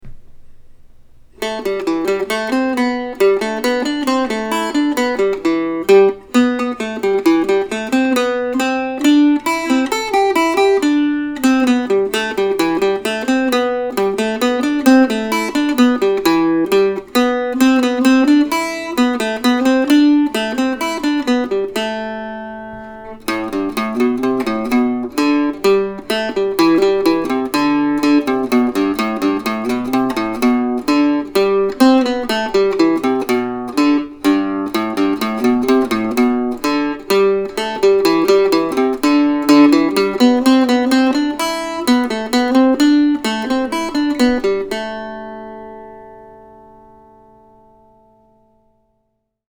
Autumn Suite, November, 2020 (for Octave Mandolin or Mandocello)